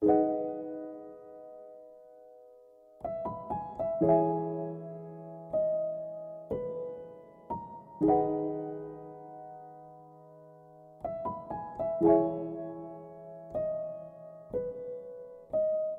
情感钢琴
描述：用实验室软钢琴制作的简单钢琴循环。
Tag: 120 bpm Hip Hop Loops Piano Loops 2.69 MB wav Key : Unknown FL Studio